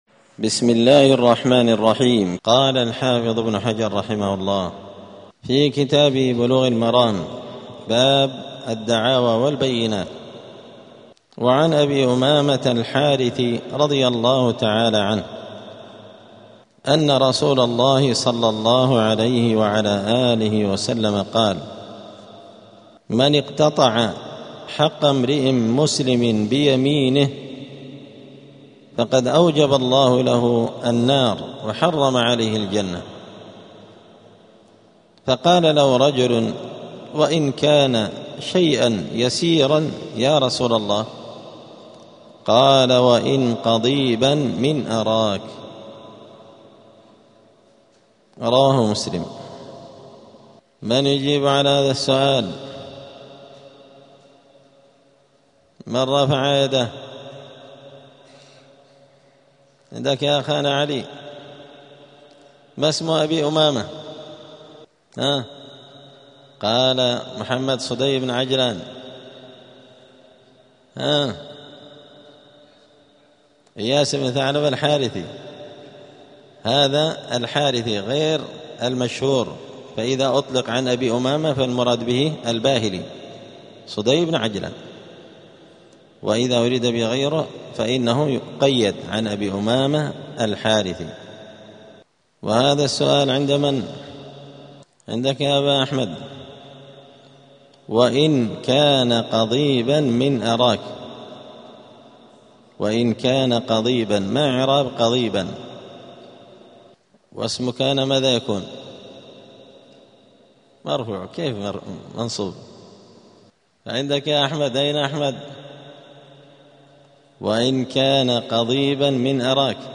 *الدرس الثالث والعشرون (23) {ﺣﻜﻢ ﻣﻦ ﺃﺧﺬ ﻣﺎﻝ ﻏﻴﺮﻩ ﺑﻐﻴﺮ ﺣﻖ}*
دار الحديث السلفية بمسجد الفرقان قشن المهرة اليمن